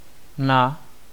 Ääntäminen
IPA : [ə.'pɒn]